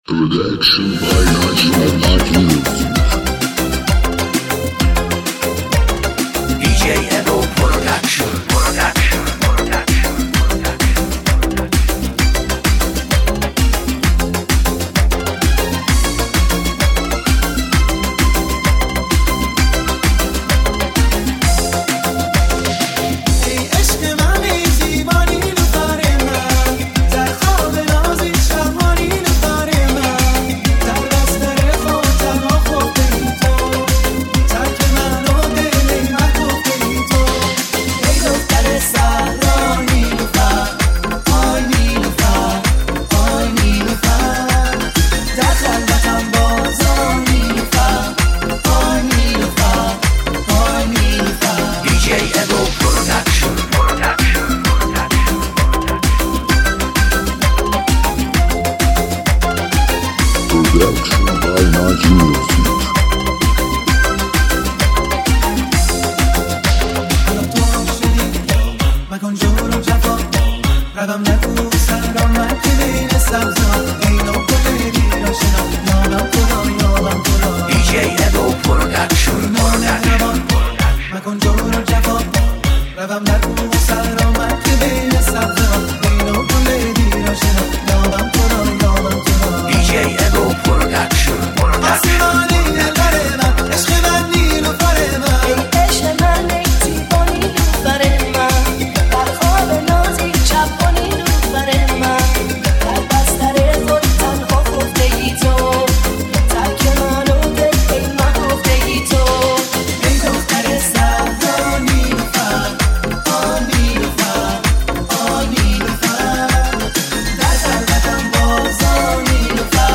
ریمیکس شاد نوستالژیک قدیمی برای رقص